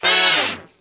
T5 " 萨克斯乐句 T53
描述：男高音萨克斯短语。我用我的笔记本电脑内置麦克风Realtek HD用Audacity播放和录制的样本。
标签： 萨克斯短语 男高音-SAX-短语 萨克斯 萨克斯 爵士 采样仪器 男高音-萨克斯
声道立体声